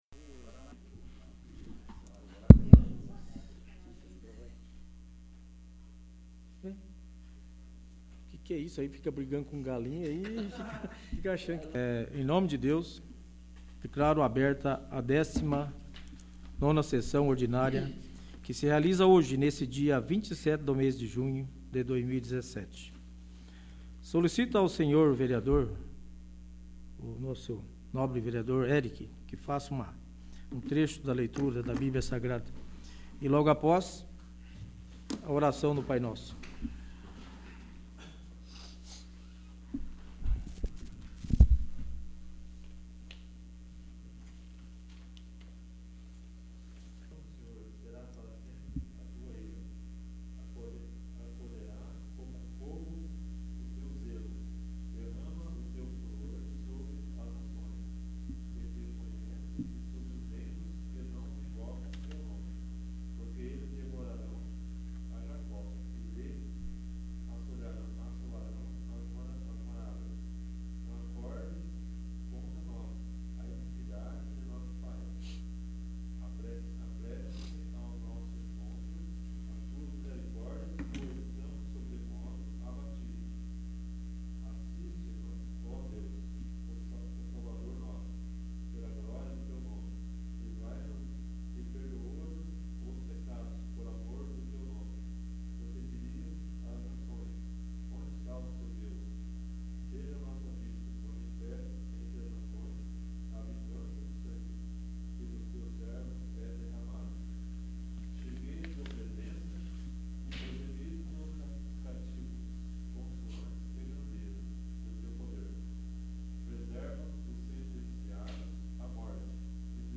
19º. Sessão Ordinária